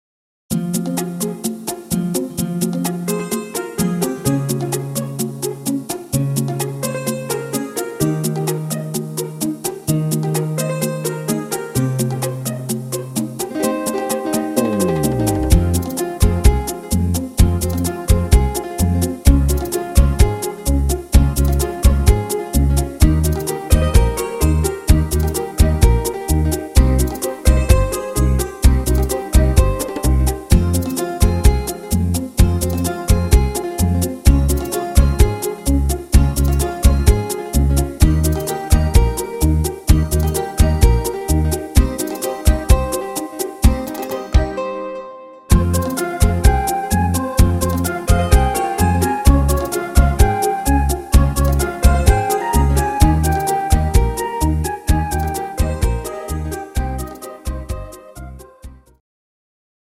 Bachata Version